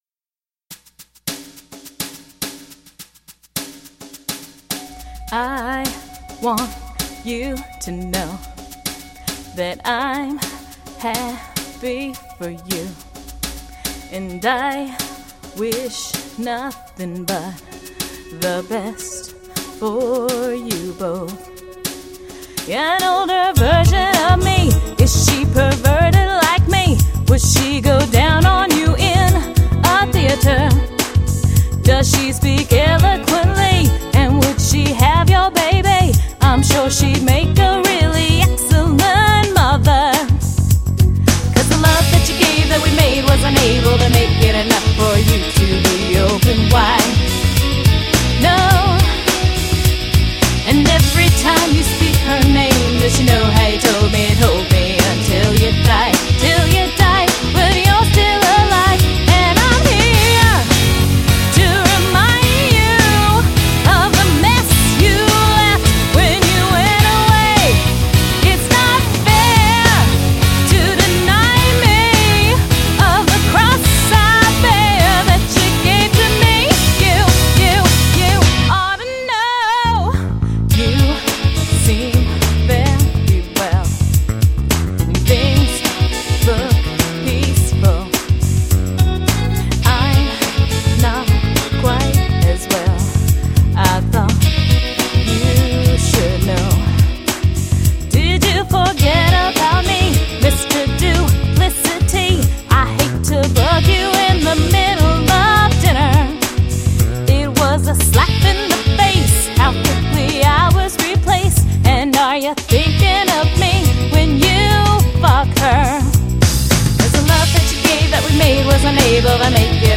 Karaoke (Vocal Overdub)